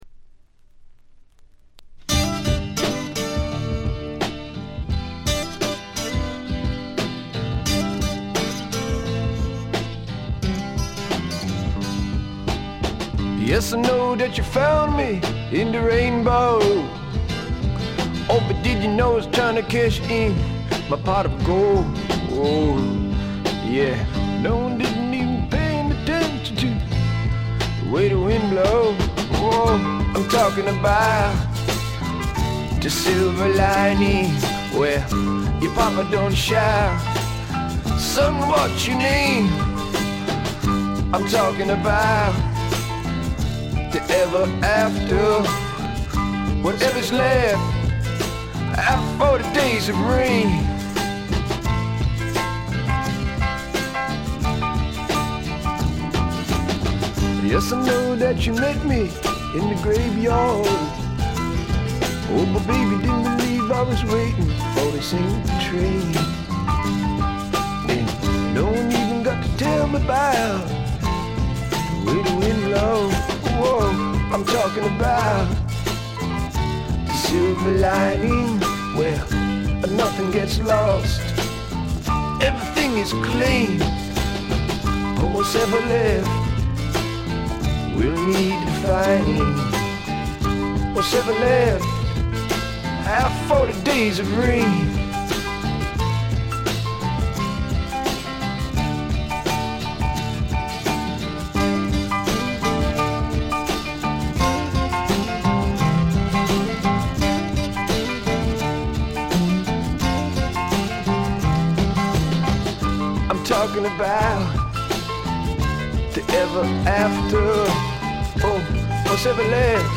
静音部（ほとんどないけど）でチリプチ少々、散発的なプツ音が2-3回出たかな？って程度。
試聴曲は現品からの取り込み音源です。